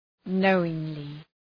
Προφορά
{‘nəʋıŋlı}